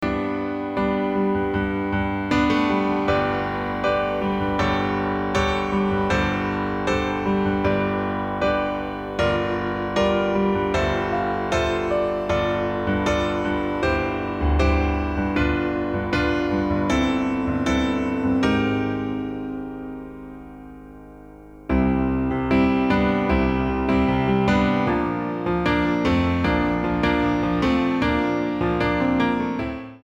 --> MP3 Demo abspielen...
Tonart:Em ohne Chor